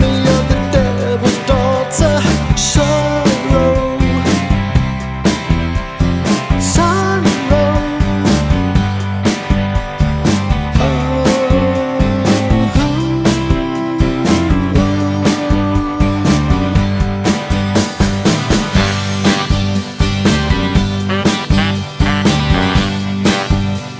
No Saxophone Solo Pop (1980s) 2:52 Buy £1.50